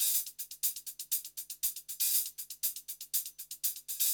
HIHAT LO10.wav